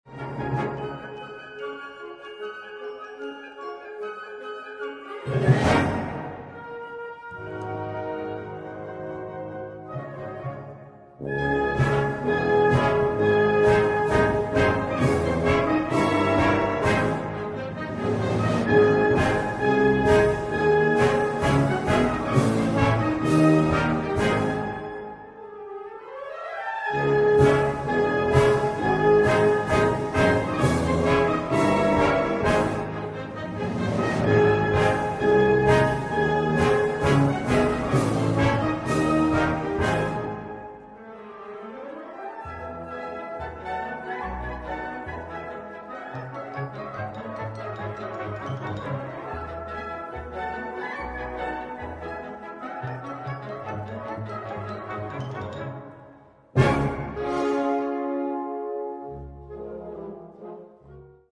D-flat Major（原調）